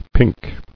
[pink]